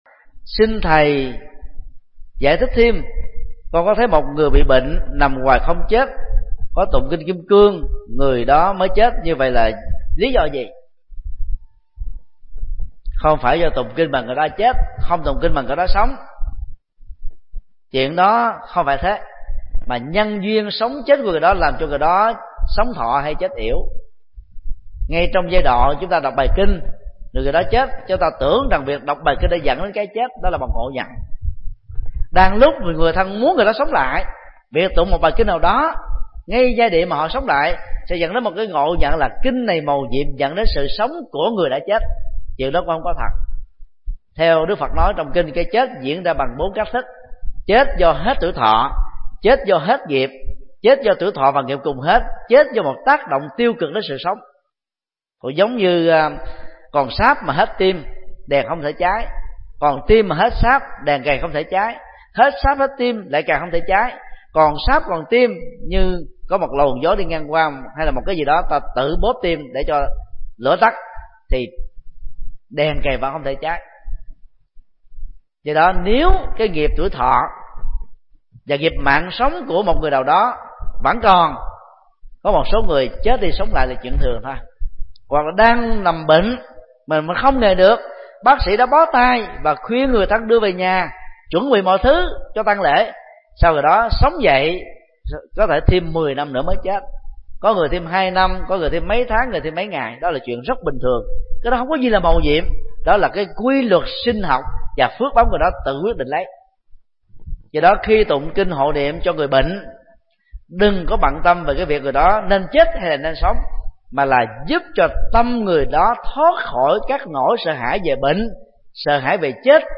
Vấn đáp: Tụng kinh cho người đang hấp hối – Thích Nhật Từ